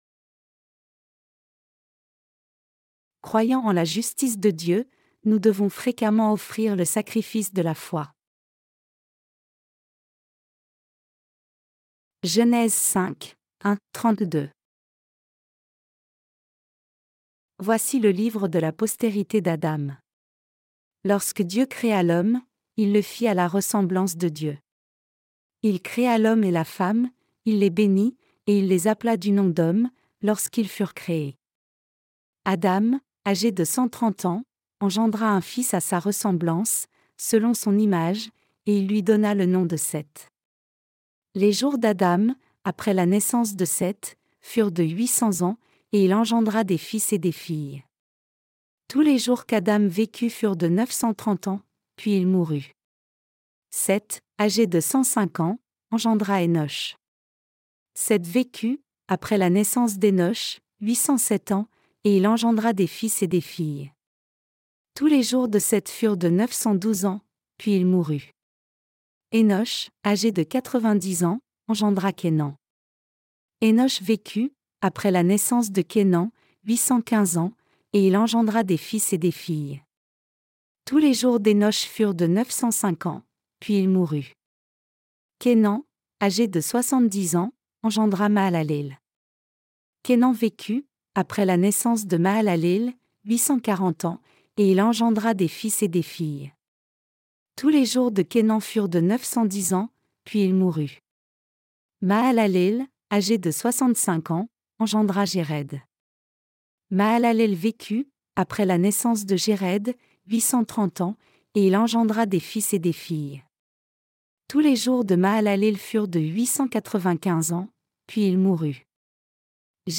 Sermons sur la Genèse (V) - LA DIFFERENCE ENTRE LA FOI D’ABEL ET LA FOI DE CAÏN 12.